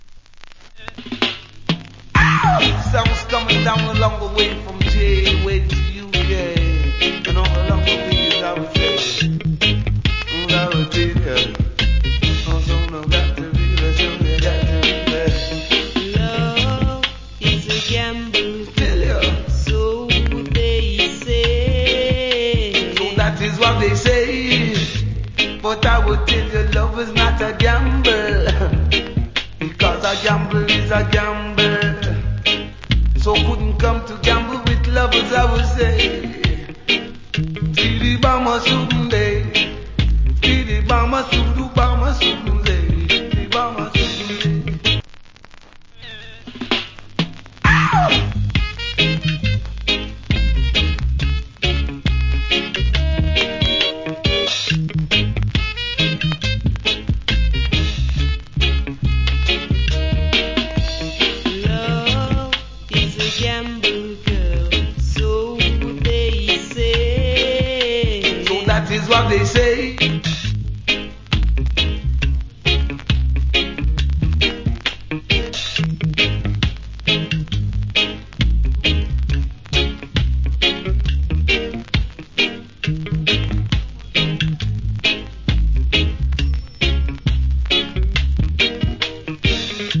Cool DJ.